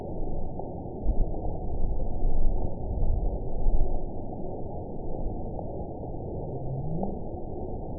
event 921765 date 12/18/24 time 23:29:51 GMT (6 months ago) score 9.37 location TSS-AB03 detected by nrw target species NRW annotations +NRW Spectrogram: Frequency (kHz) vs. Time (s) audio not available .wav